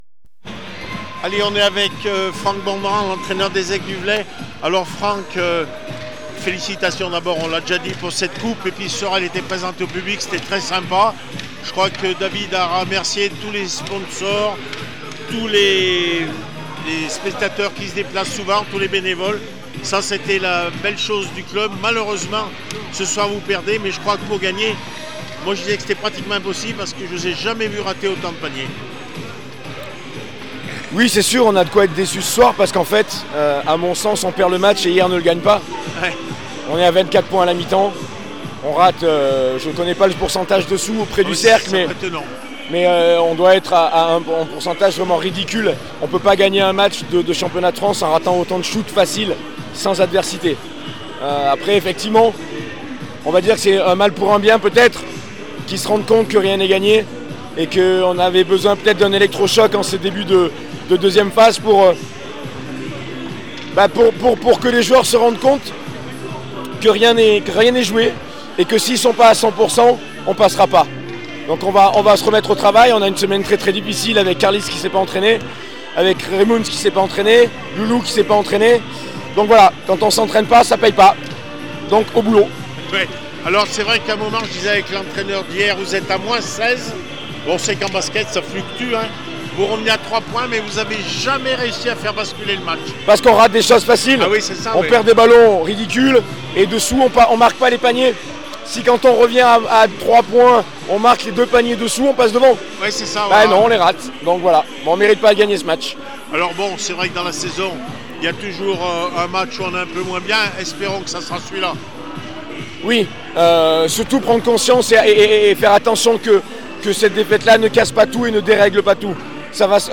handi basket les aigles du velay 61-65 hyeres handibasket réactions après match